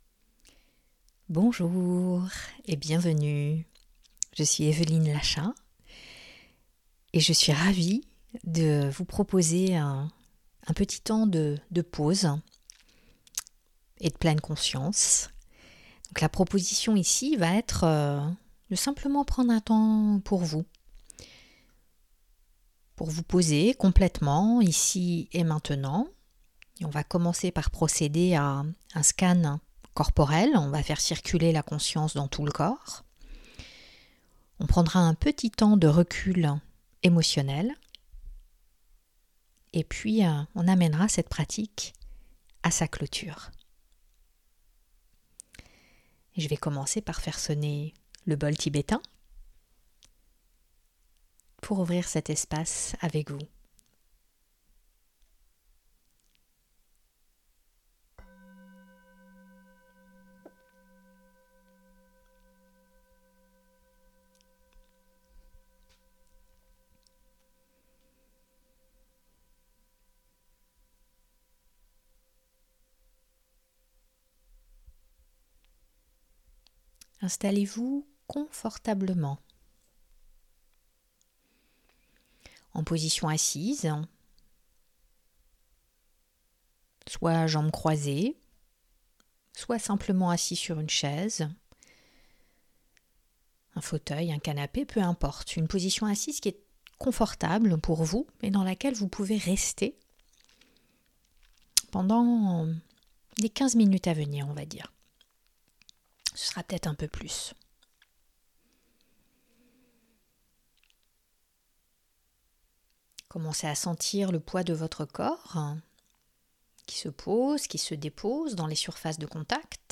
Pause mindfulness avec un scan corporel guidé que j’ai le plaisir de vous offrir